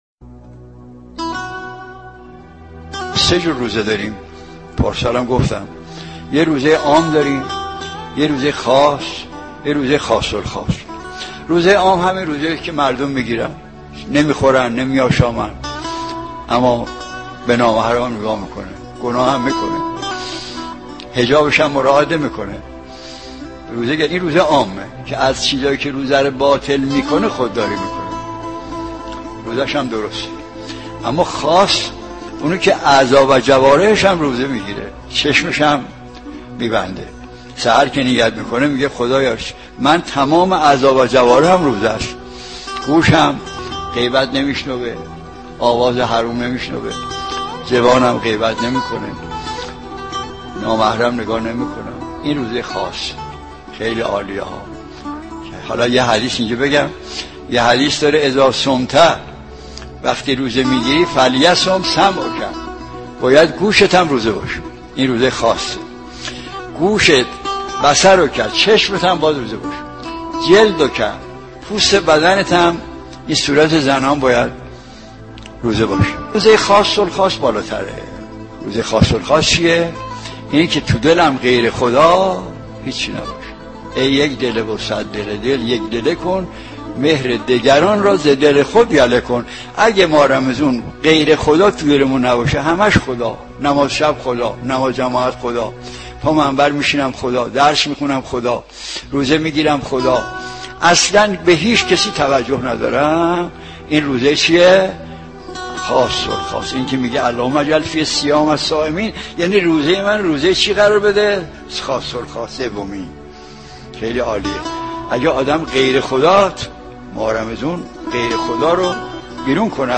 در یکی از سخنرانی‌های خود